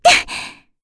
Frey-Vox_Jump.wav